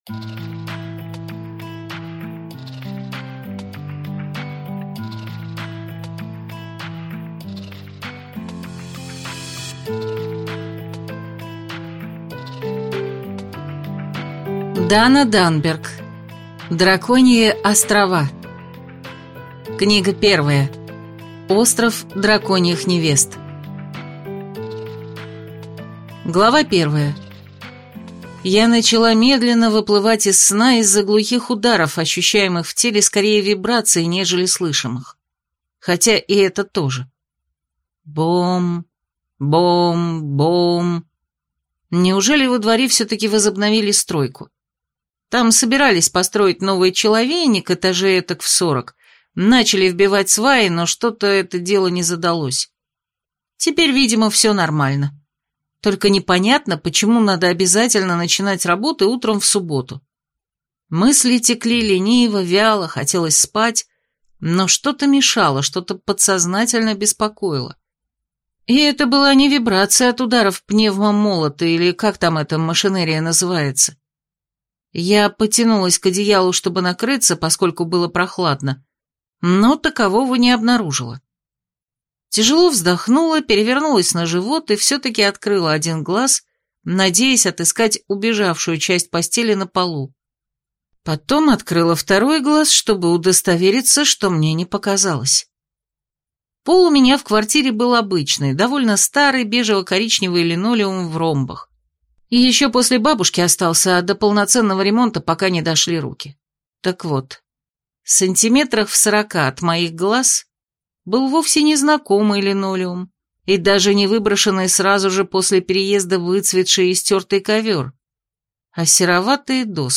Аудиокнига Остров драконьих невест. Книга 1 | Библиотека аудиокниг